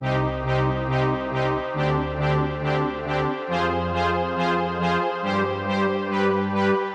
陷阱弦乐 138BPM
Tag: 138 bpm Trap Loops Strings Loops 1.17 MB wav Key : C FL Studio